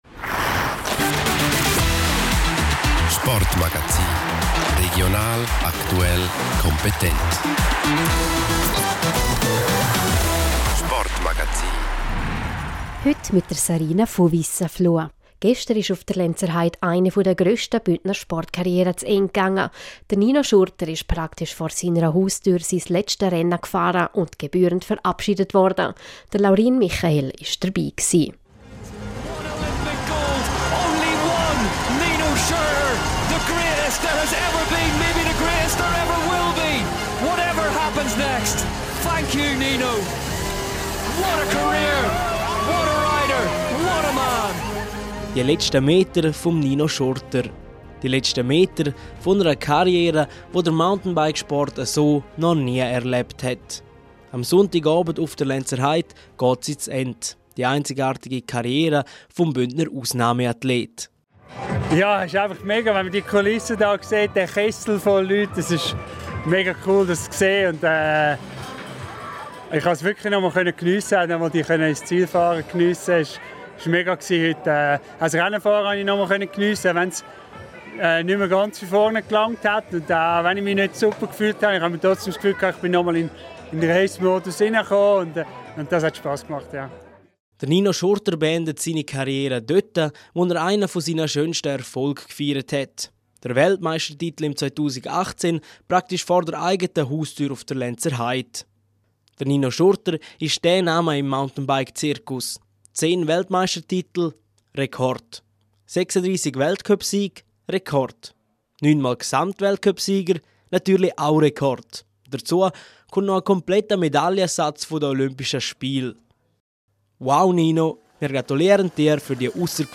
• «Nino, Nino, Nino»: Tausende Fans feierten auf der Lenzerheide den erfolgreichsten Mountainbiker aller Zeiten.